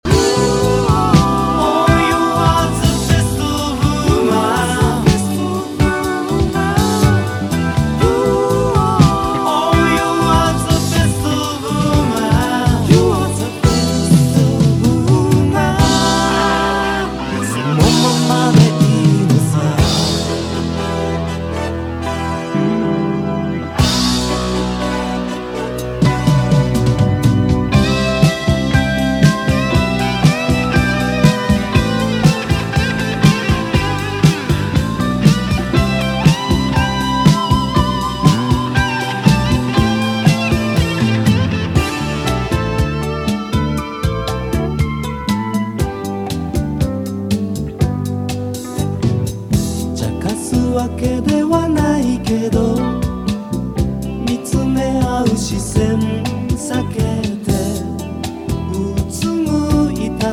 ELECTONE / JAPANESE FUSION
エレクトーン使いの和モダン・ブギー！